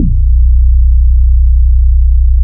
MAY BE BASS.wav